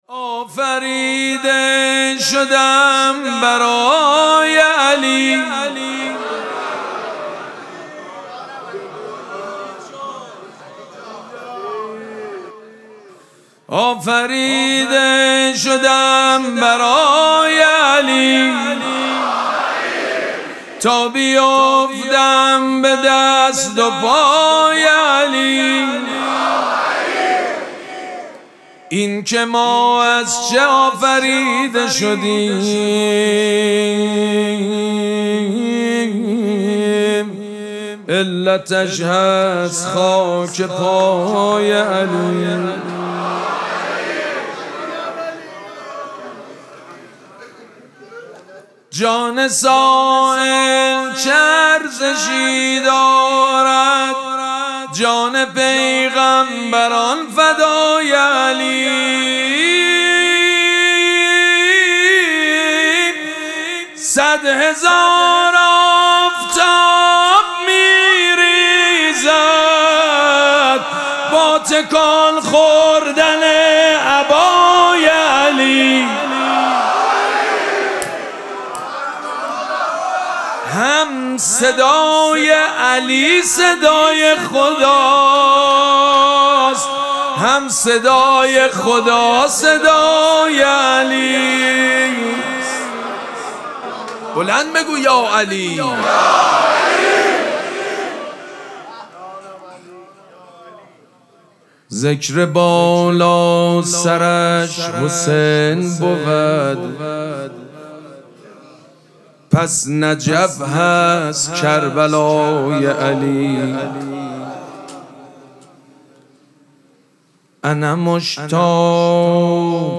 مراسم مناجات شب بیست و یکم ماه مبارک رمضان
شعر خوانی
مداح
حاج سید مجید بنی فاطمه